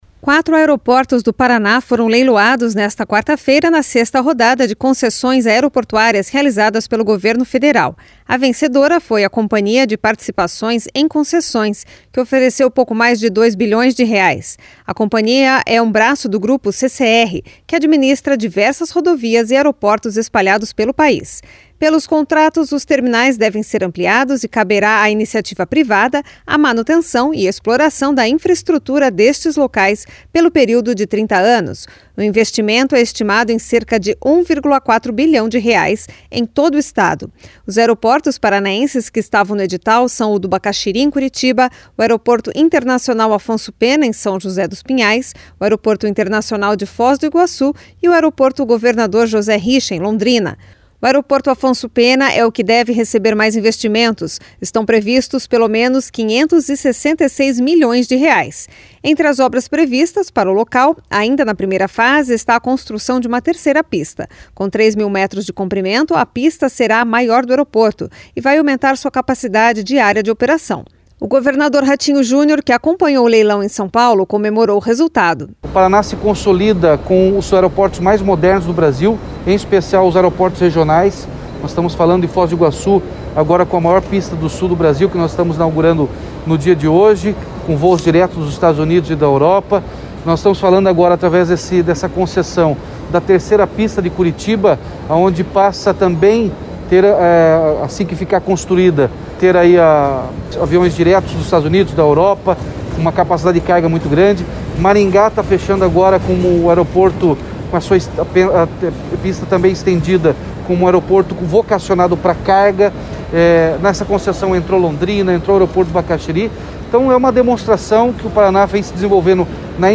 O governador Ratinho Junior, que acompanhou o leilão em São Paulo, comemorou o resultado.